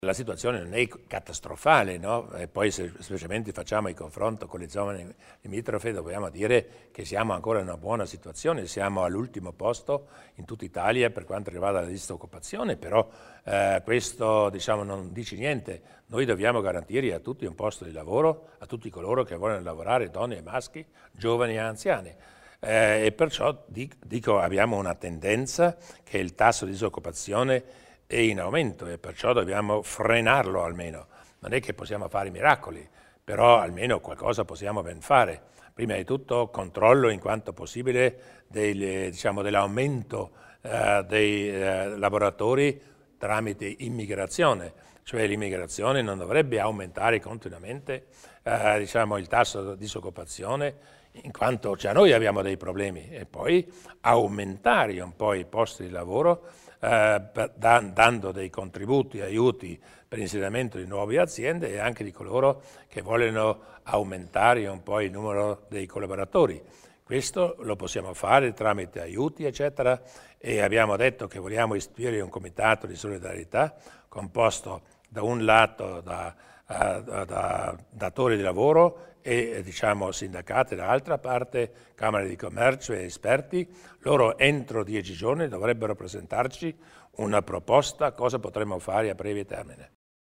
Il Presidente Durnwalder spiega gli interventi in tema di contrasto alla disoccupazione